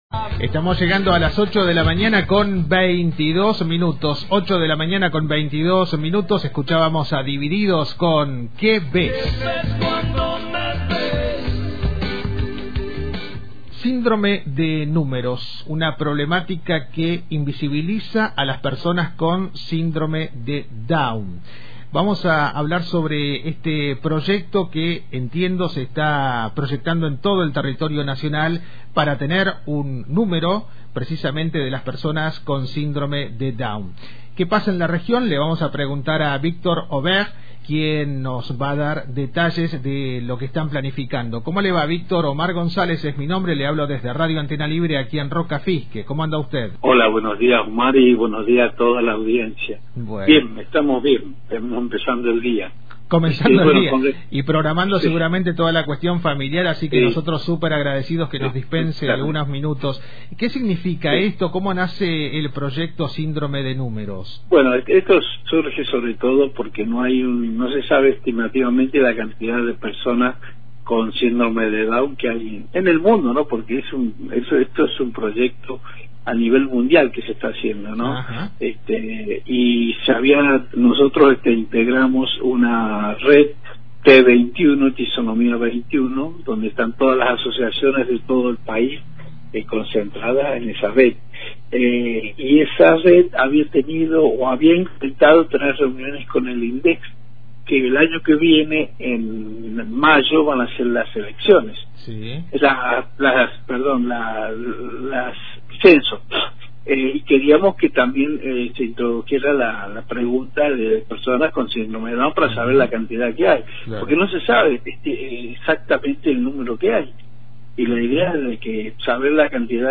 En comunicación con Antena Libre